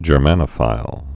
(jər-mănə-fīl)